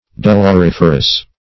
Search Result for " doloriferous" : The Collaborative International Dictionary of English v.0.48: Doloriferous \Dol`or*if"er*ous\, a. [L. dolor pain + -ferous.]